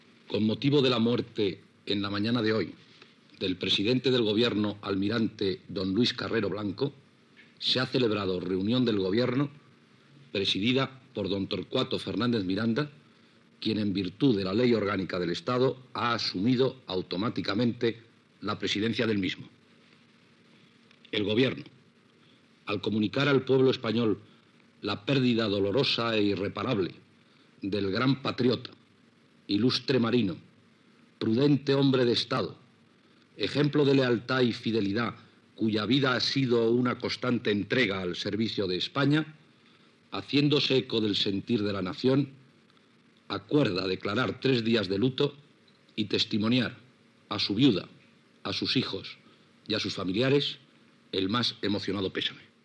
El ministre d'Información y Turismo Fernando de Liñán comunica la reunió de govern després de la mort del president del govern, almirall Luis Carrero Blanco
Informatiu
Extret del programa "El sonido de la historia", emès per Radio 5 Todo Noticias el 22 de desembre de 2012